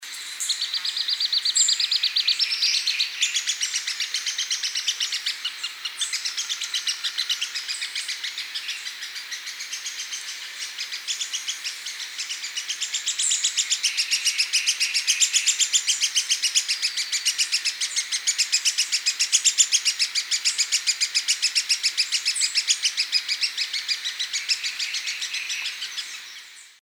4. Broad-Winged Hawk (Buteo platypterus)
• Call: A thin, high-pitched whistle:
XC80453-Lesser-Woodcreeper-Xiphorhynchus-fuscus-fuscus.mp3